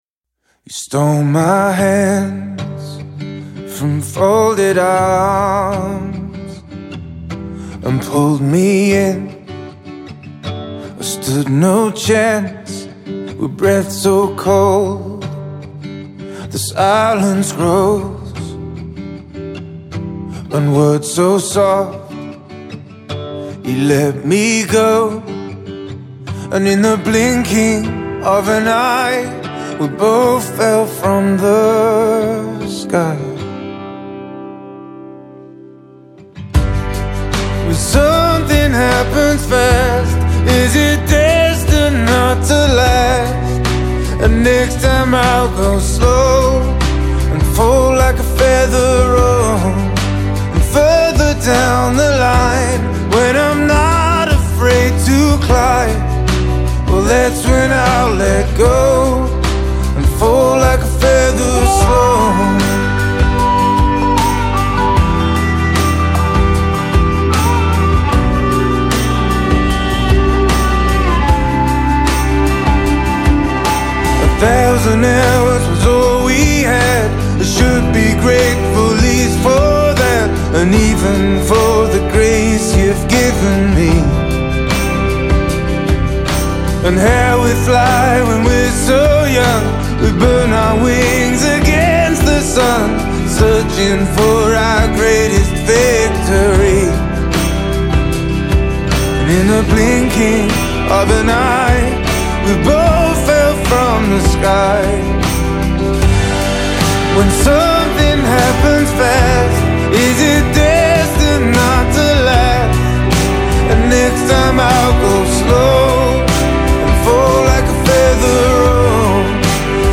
Жанр: foreignbard